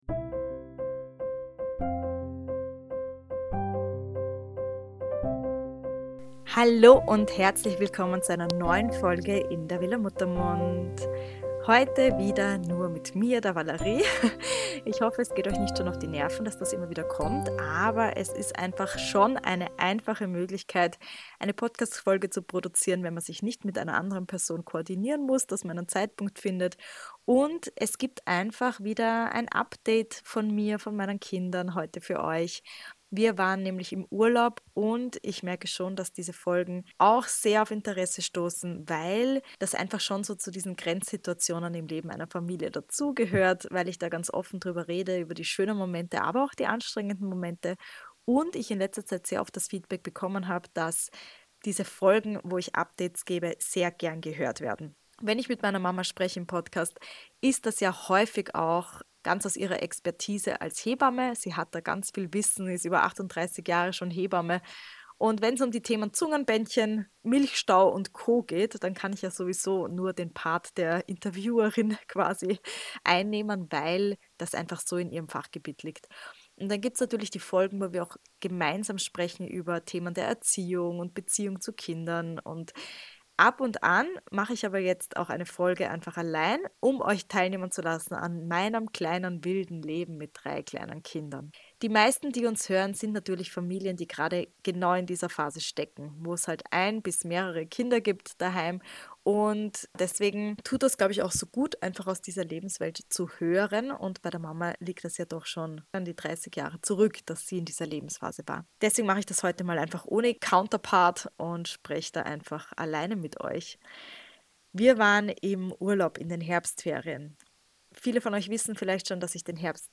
In dieser Solo-Folge